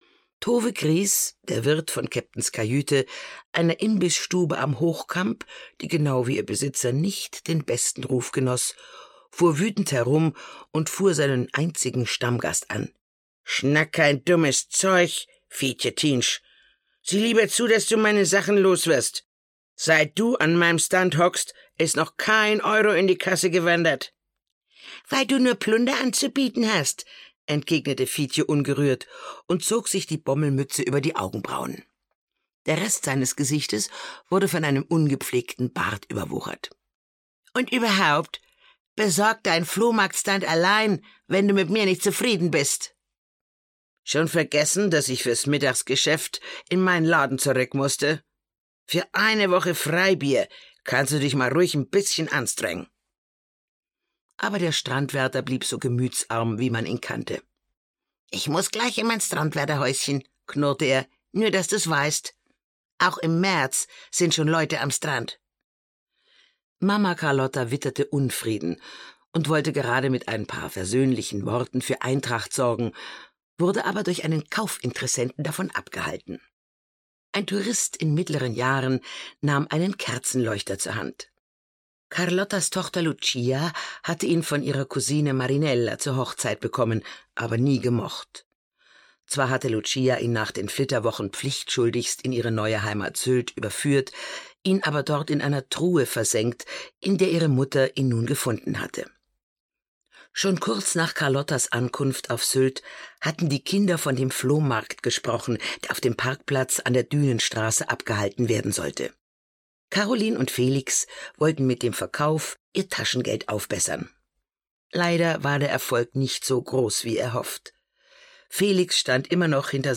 Strandläufer (Mamma Carlotta 8) - Gisa Pauly - Hörbuch